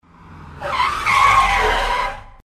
На этой странице собраны звуки тормозов машин в разных ситуациях: от резкого экстренного торможения до плавного замедления.
Резкий звук торможения автомобиля